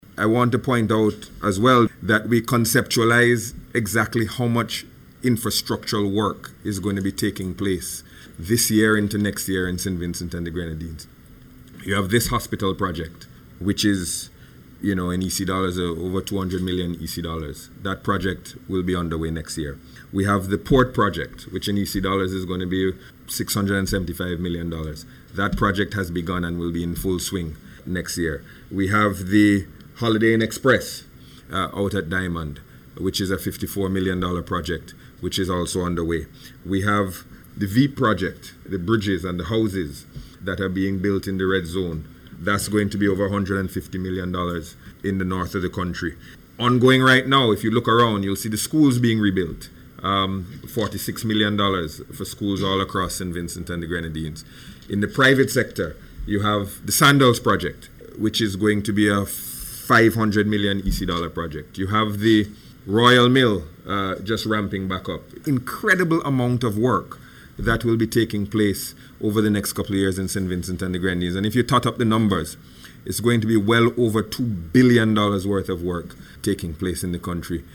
This is according to Minister of Finance Camillo Gonsalves as he spoke about investments being made in the Healthcare Sector at a Press Briefing on Tuesday at Cabinet Room.